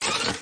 RoverHop1.mp3